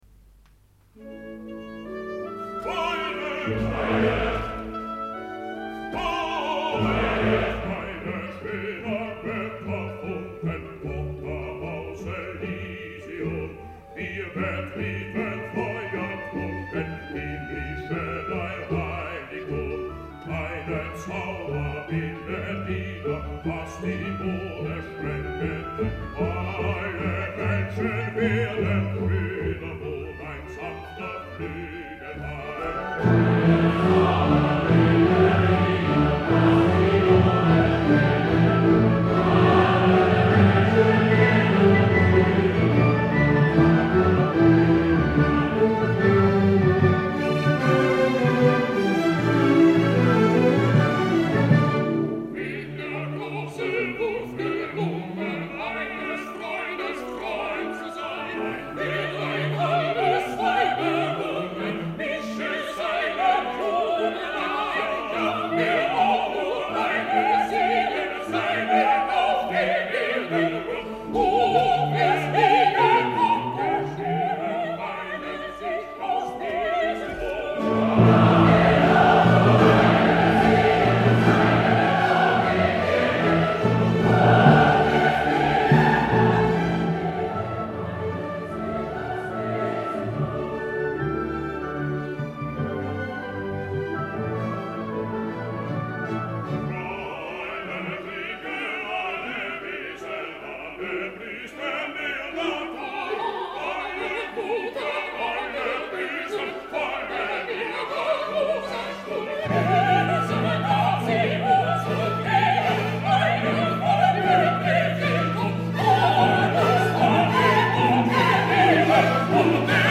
C’est le moment si poignant où le baryton entonne